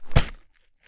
Added ball sfx
sand3.wav